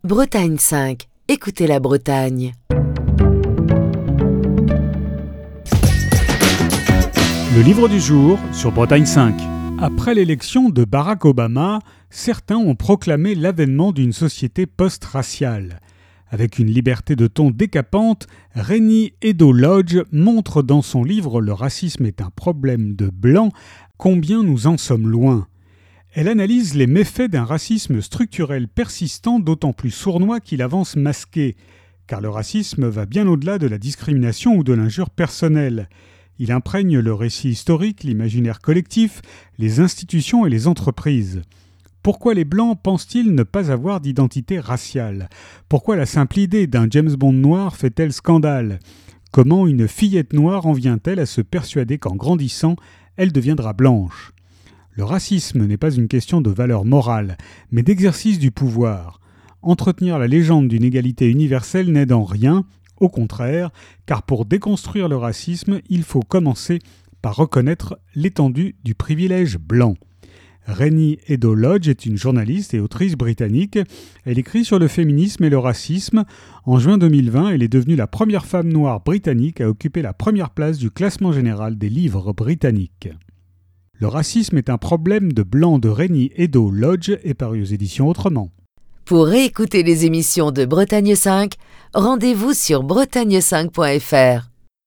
Chronique du 12 novembre 2021.